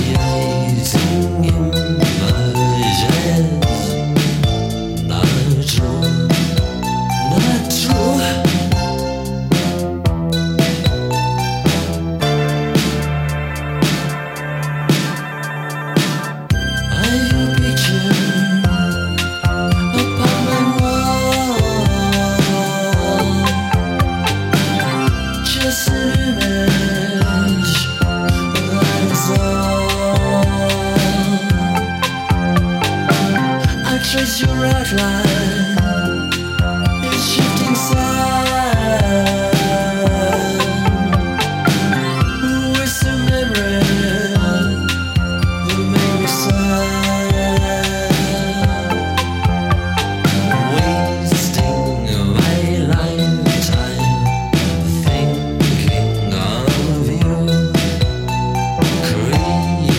Electro Wave